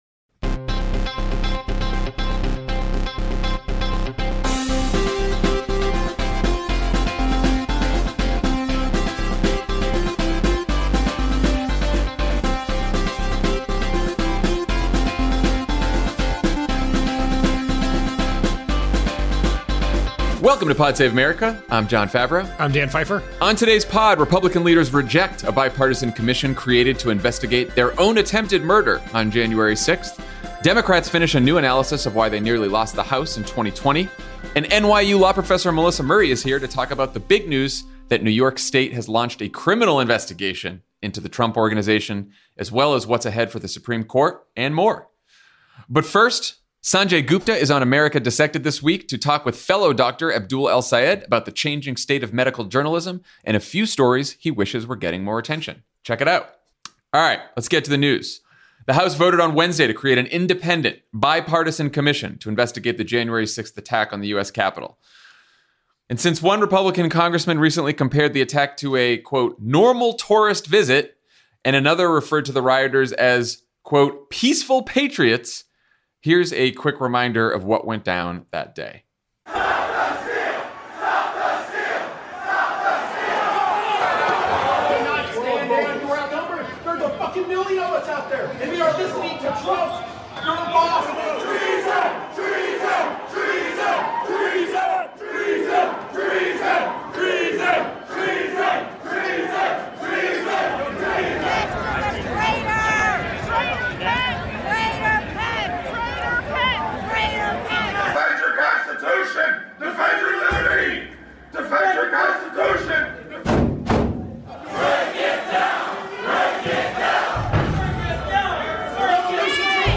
Republican leaders reject a bipartisan commission created to investigate their own attempted murder on 1/6, Democrats finish a new analysis of why they nearly lost the House in 2020, and NYU Law Professor Melissa Murray talks to Jon Favreau about the news that New York State has launched a criminal investigation into the Trump organization, as well as what’s ahead for the Supreme Court.